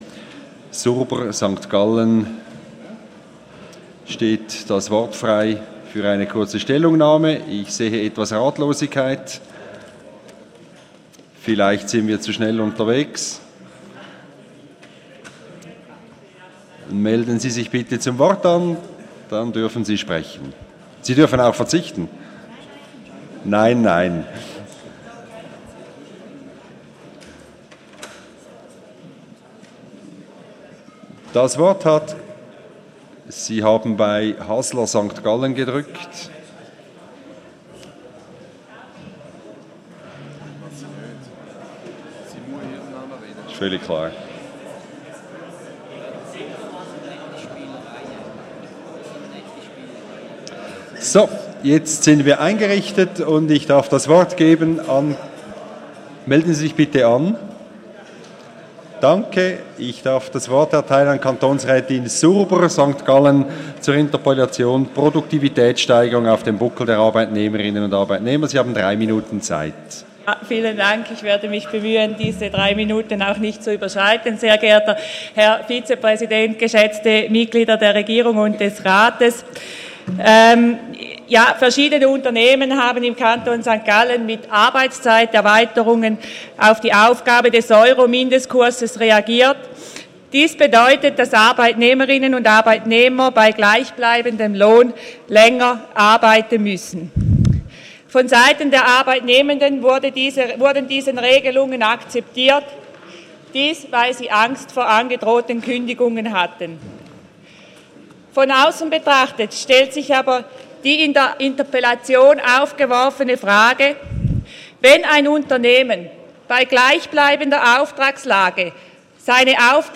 16.9.2015Wortmeldung
Session des Kantonsrates vom 14. bis 16. September 2015